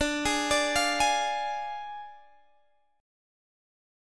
D Diminished